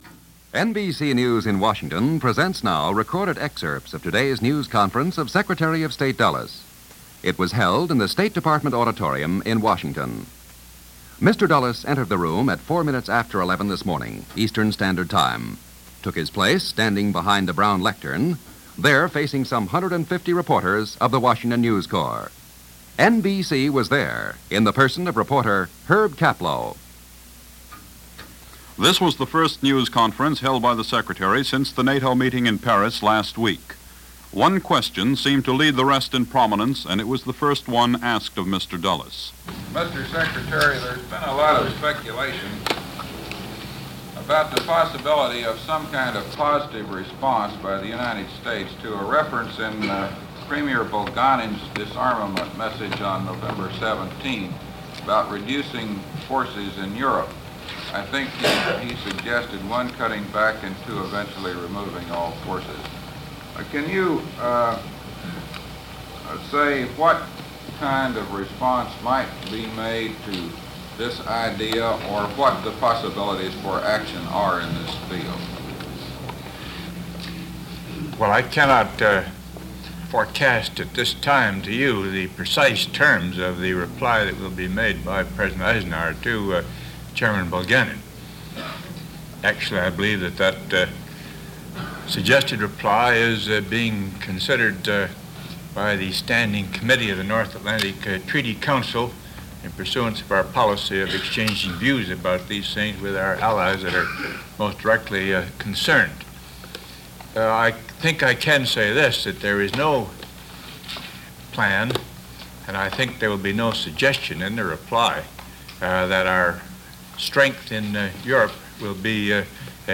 John Foster Dulles and the Cold War Press Conference - Secretary of State Dulles reporting on the recent NATO meeting and nuclear capabilities.
John-Foster-Dulles-Presser-1956.mp3